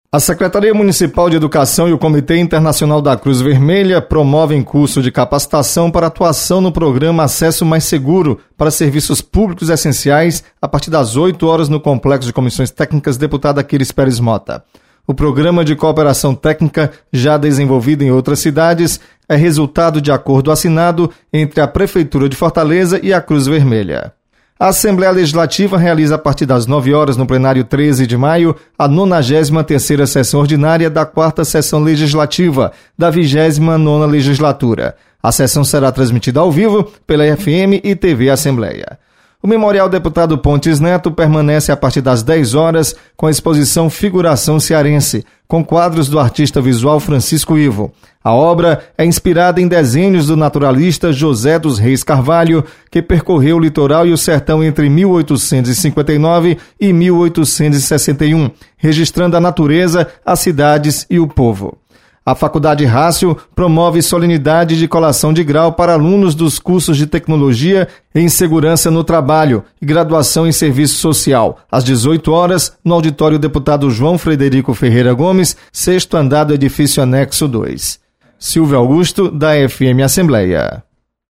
Acompanhe as informações das atividades da Assembleia Legislativa nesta terça-feira. Repórter